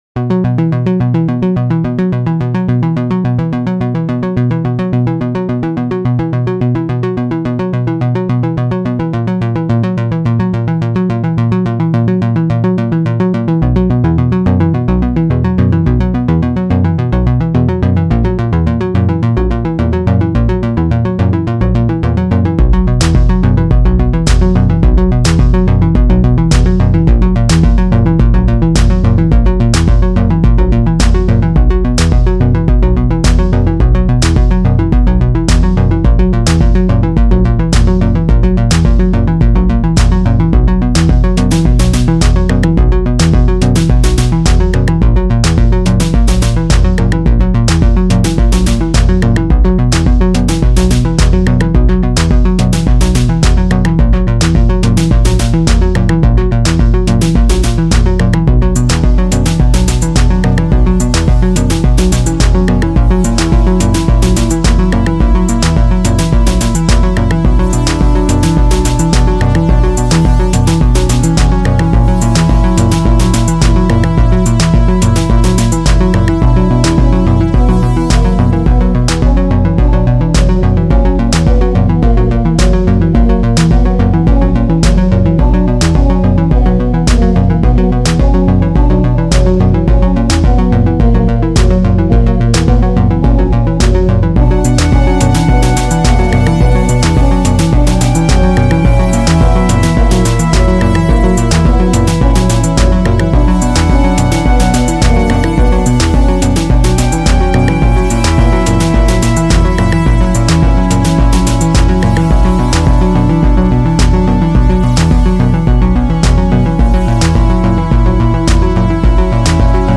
Beaucoup de mystère, beaucoup de classe.